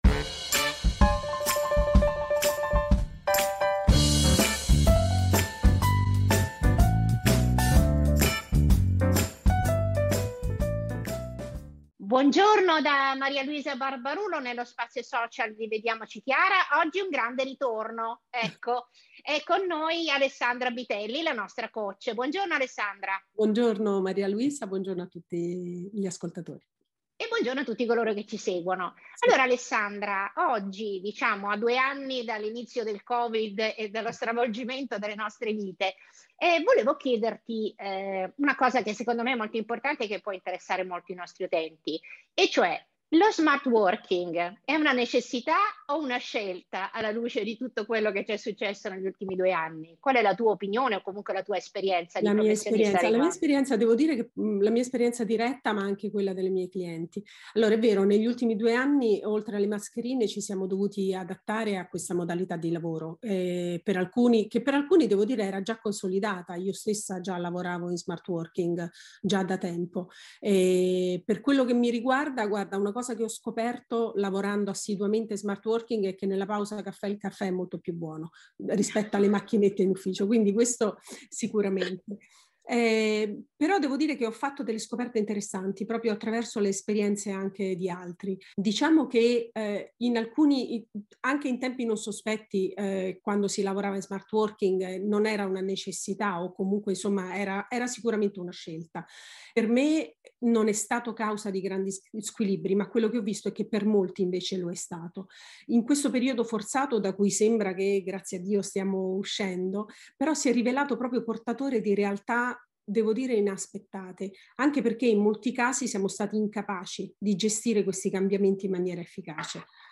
Trainer & Coach sono estratte da interviste